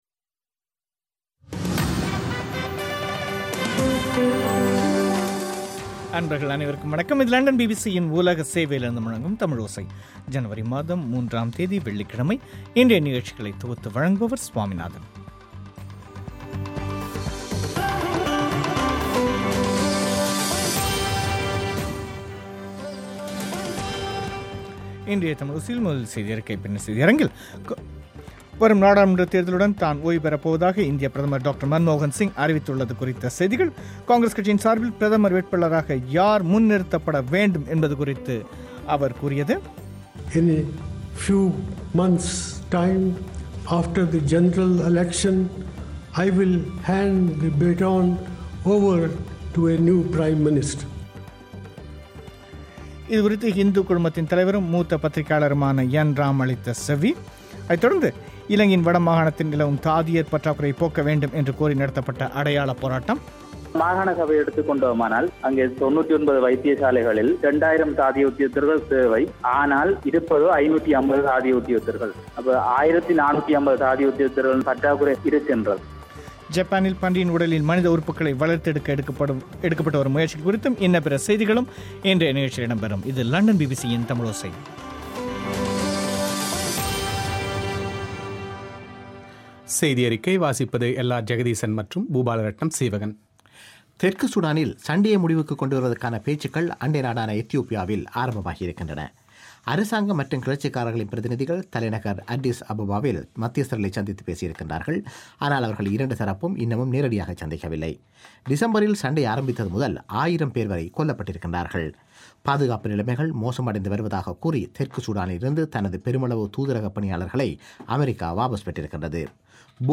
இன்றைய தமிழோசையில் இடம்பெற்ற முக்கியச் செய்திகள். இந்தியப் பிரதமர் மன்மோகன் சிங் தேர்தலுக்குப் பிறகு பதவியில் இருந்து விலகிச் செல்வதாகக் கூறியுள்ளது குறித்த செய்திகள். இது குறித்து இந்து குழுமத்தின் தலைவர் என் ராம் அளித்த செவ்வி.